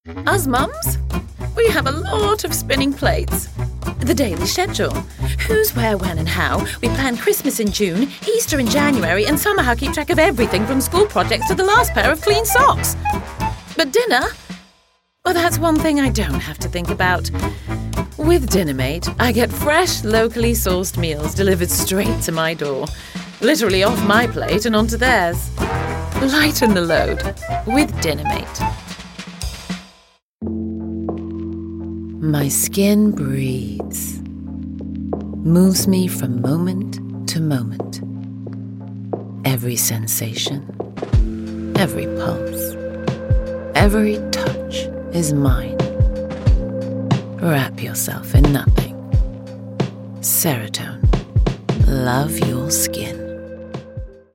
british rp | natural
Victoria_Ekanoye_RP_Accent.mp3